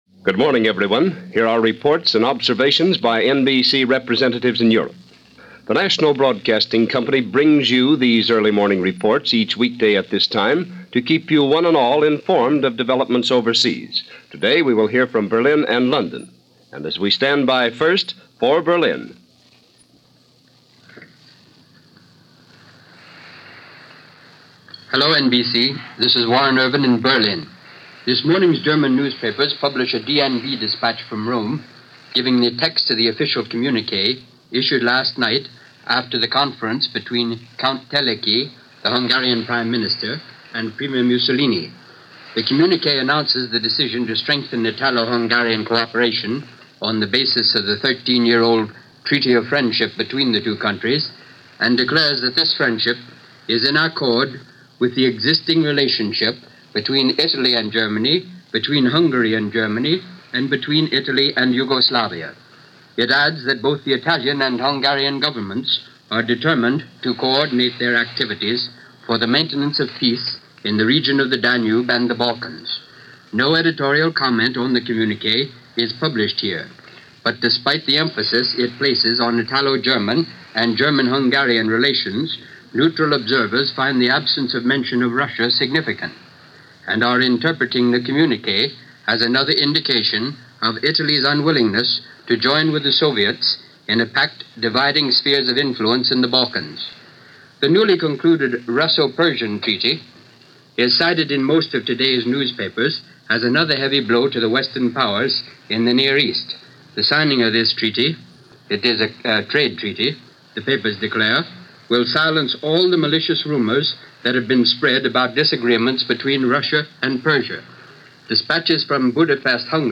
Marh 27, 1940 - Hungary Joins Axis - Paris Expels Russians - American labor and the DOJ - News for this day in 1940 - NBC Radio News Of The World.